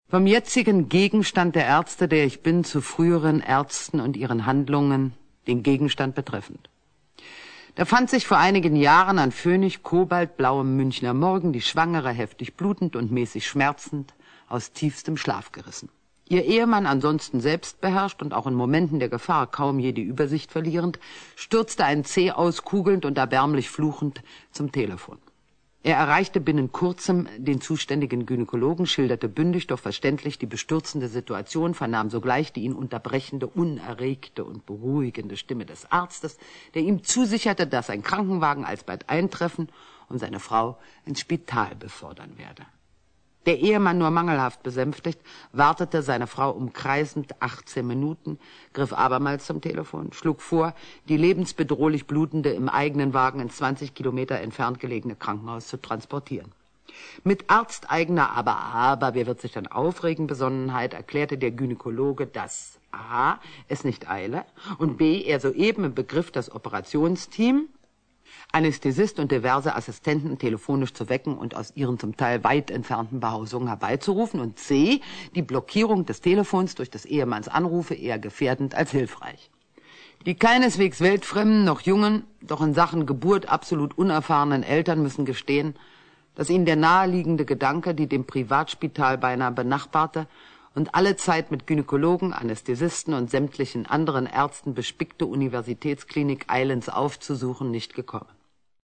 Hildegard Knef (Sprecher)
Bei dem Hörbuch handelt es sich um eine gekürzte Lesefassung, die erstmals 1975 bei Philips auf Vinyl erschien. Von der Autorin selbst zusammengestellt und gelesen, enthält es die wichtigsten Stationen ihrer Krankengeschichte.